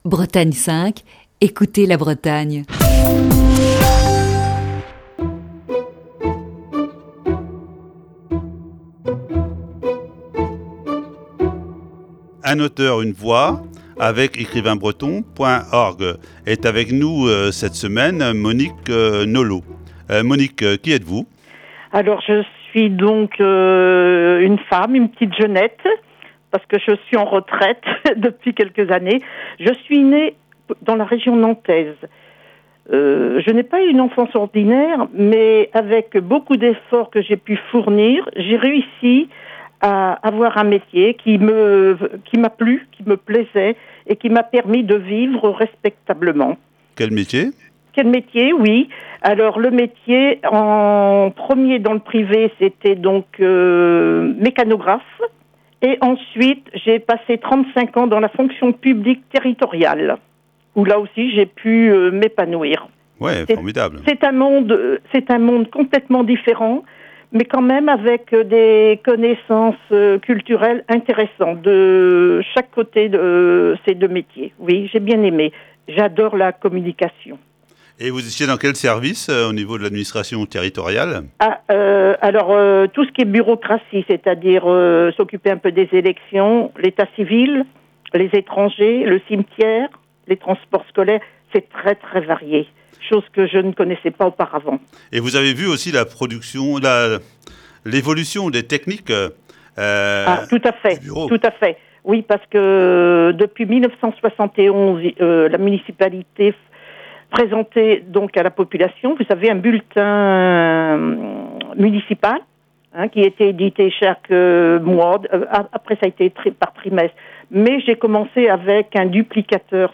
Voici ce lundi, la première partie de cet entretien.